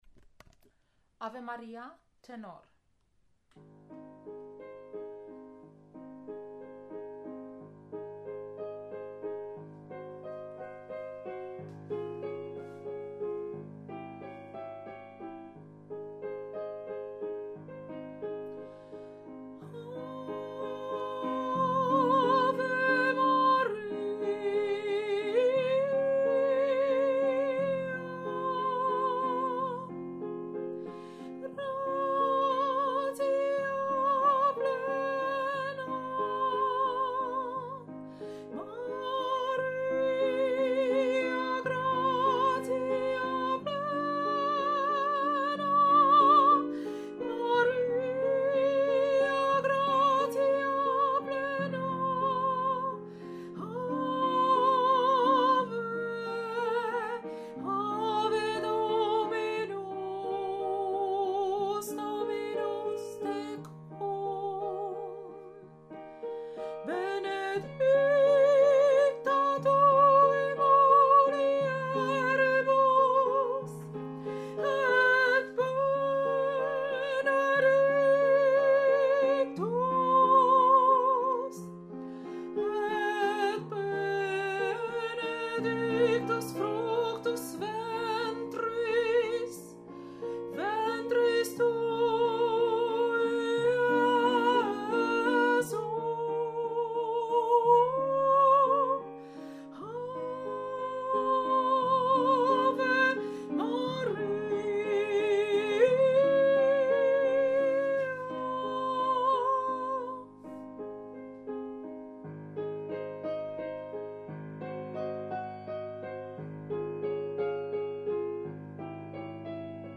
Ave Maria – Tenor
Ave-Maria-Tenor.mp3